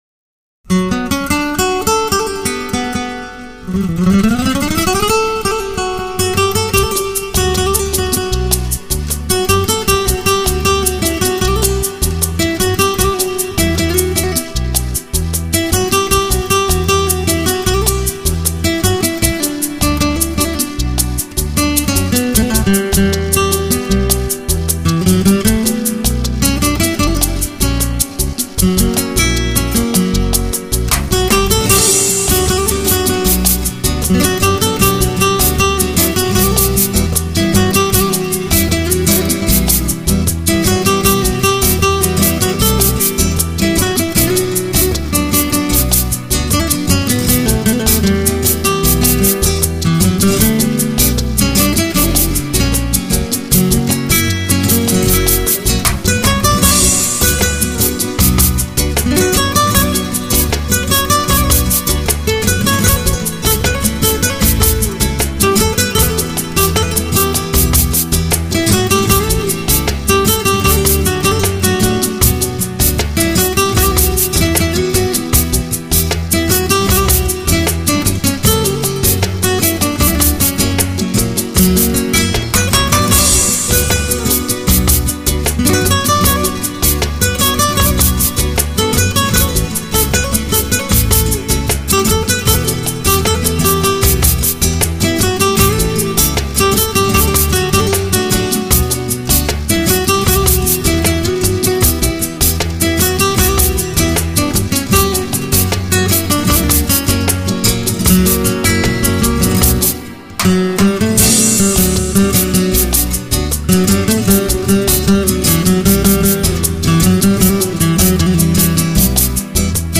Жанр: Nuevo Flamenco